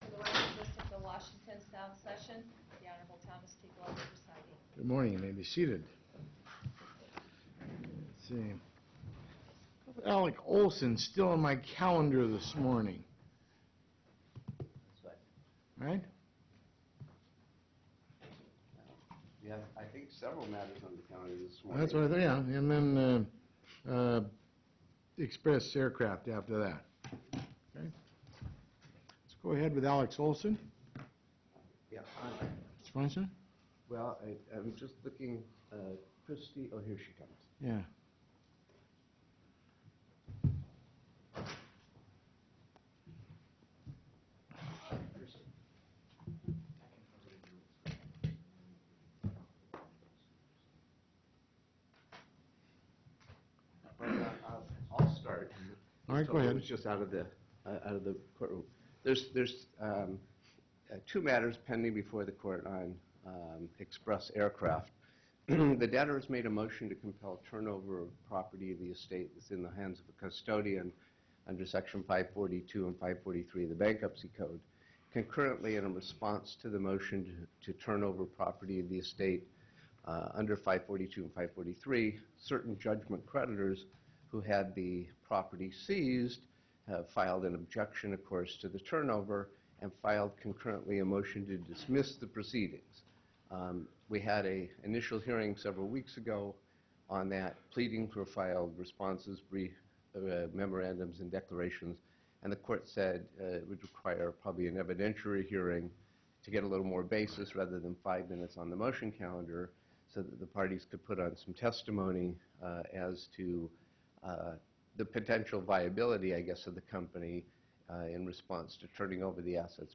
Express_Hearing.mp2